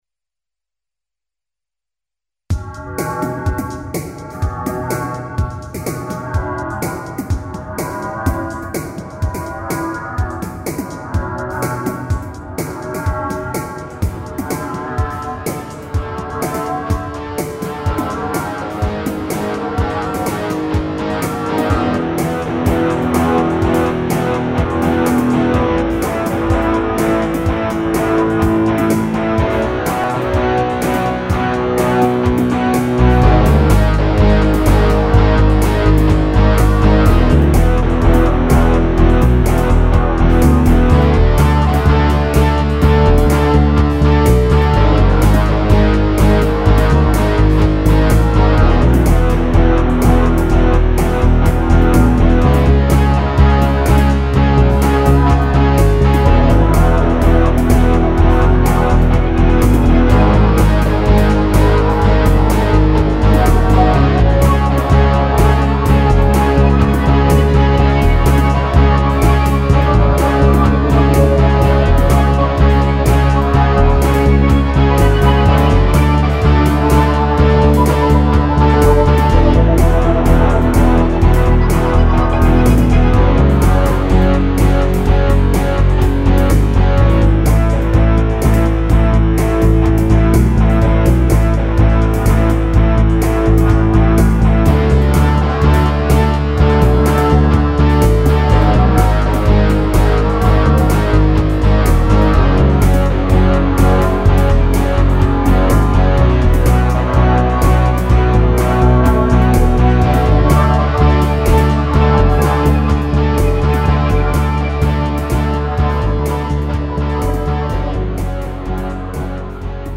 Hopalong (2.51) Mi (E) -  125
Plugins :  Font12! , Evm Bass Line , MDA EPiano , Synth1 ,
Drum loop : Arythm
Mode : Mixolydien